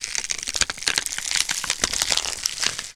ALIEN_Insect_17_mono.wav